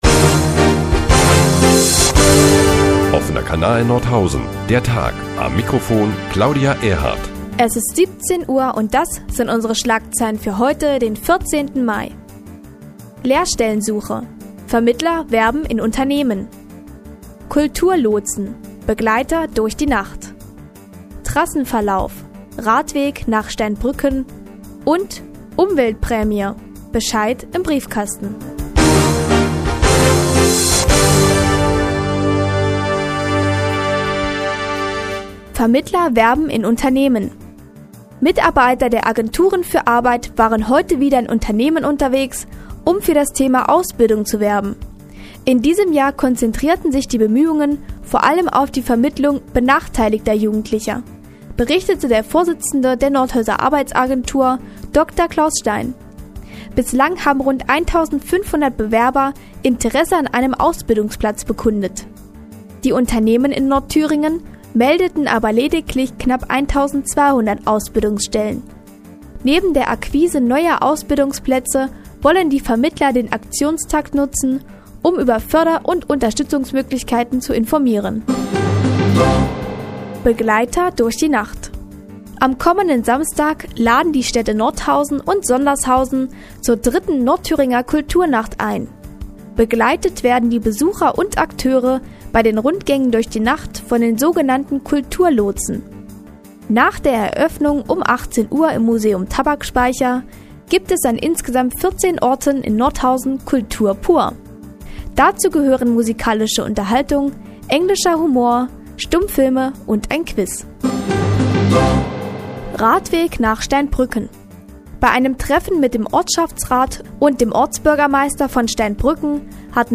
Die tägliche Nachrichtensendung des OKN ist nun auch in der nnz zu hören. Heute unter anderem mit Lotsen in der Kulturnacht und Reservierungsbescheiden für die Abwrackprämie.